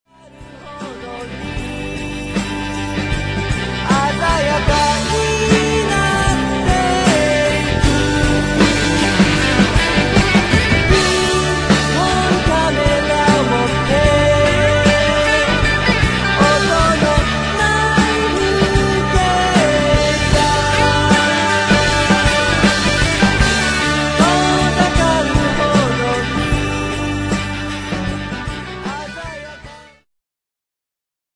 ジャンル Progressive
ギターフューチュア
ボーカルフューチュア